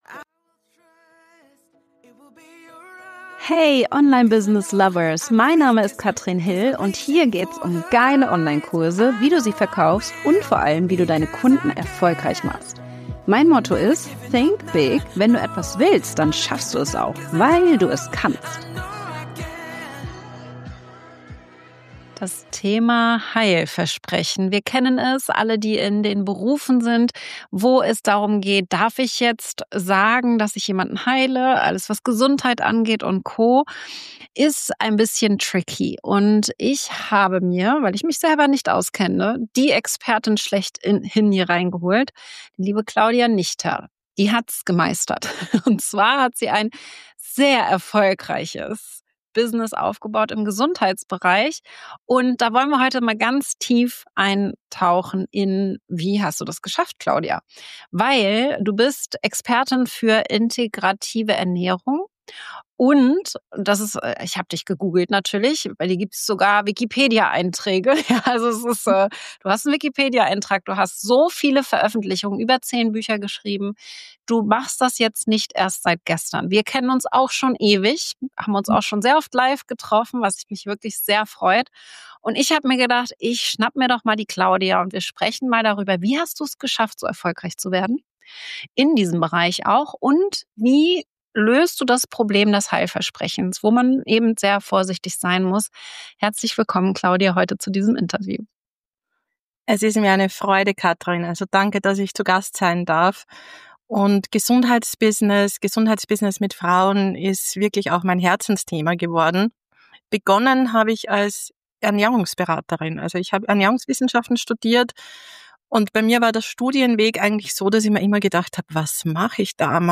ein sehr offenes Gespräch